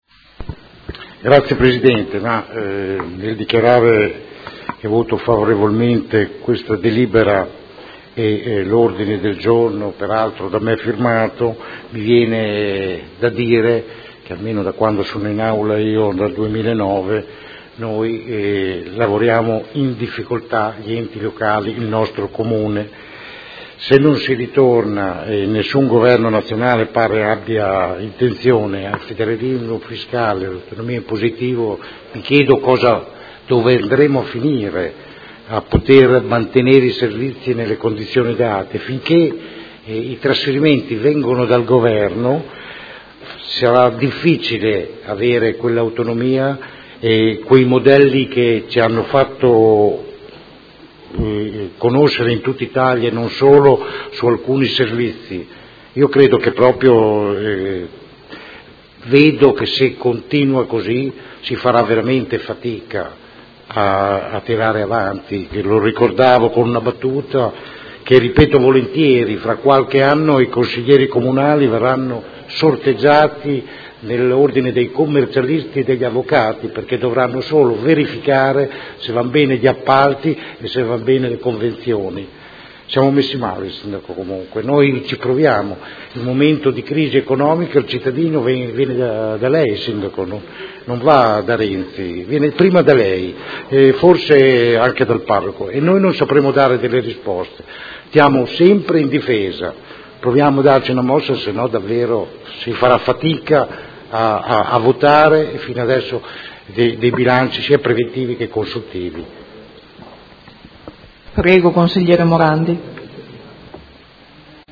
Seduta del 28/04/2016. Proposta di deliberazione: Rendiconto della gestione del Comune di Modena per l’esercizio 2015 – Approvazione. Dichiarazioni di voto